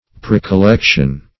Precollection \Pre`col*lec"tion\, n. A collection previously made.